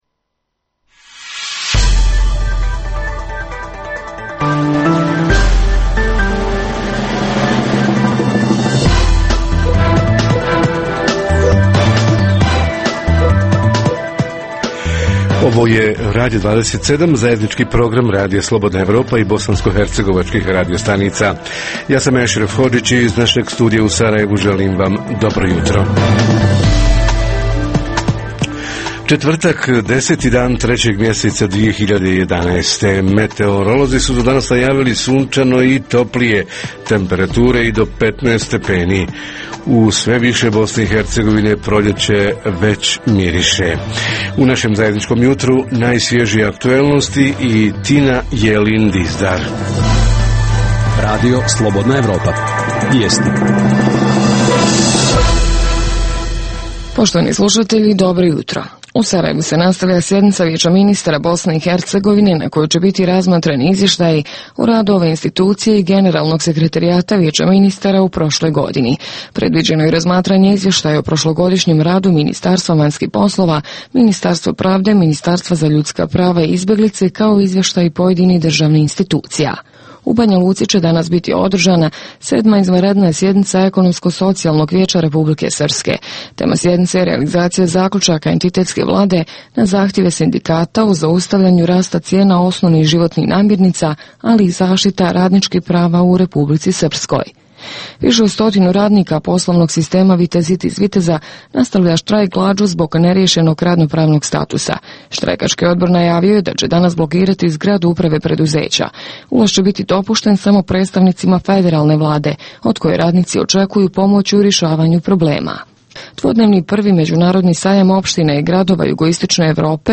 Ekonomsko-socijalni položaj građana (radnika, penzionera, studenata, invalida…..) - gdje je rješenje – protesti ili pregovori? Reporteri iz cijele BiH javljaju o najaktuelnijim događajima u njihovim sredinama.
Redovni sadržaji jutarnjeg programa za BiH su i vijesti i muzika.